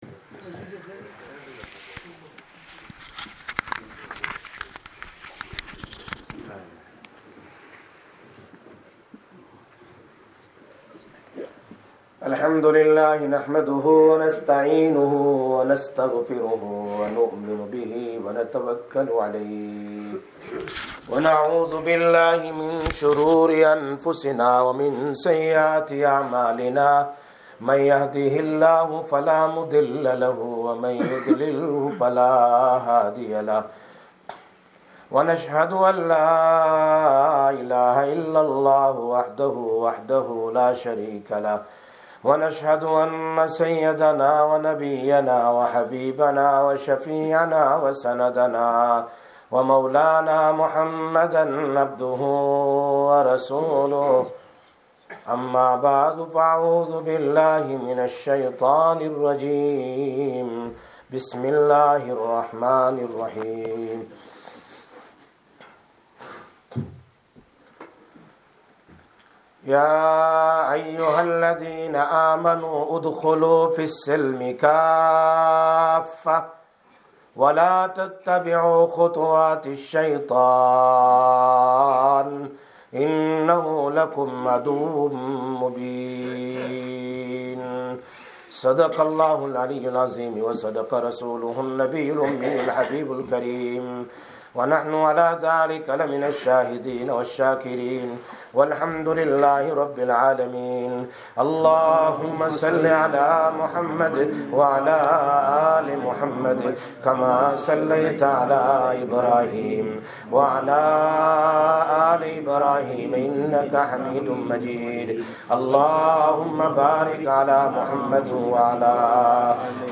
07/07/17 Jumma Bayan, Masjid Quba